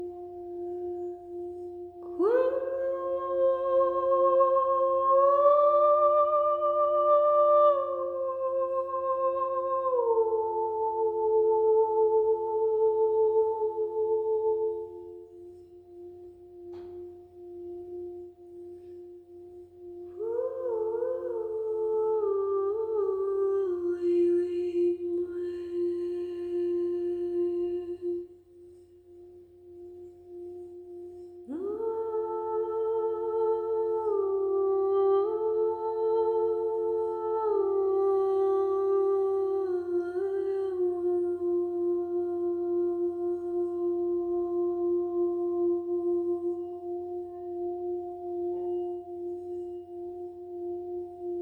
Bol Chantant et Voix                    Durée 08:30